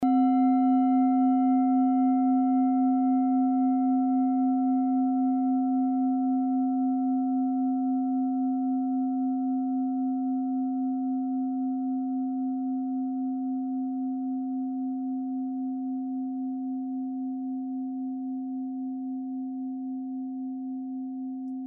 Klangschalen-Typ: Bengalen
Klangschale Nr.4
Gewicht = 850g
Durchmesser = 16,6cm
(Aufgenommen mit dem Filzklöppel/Gummischlegel)
klangschale-set-1-4.mp3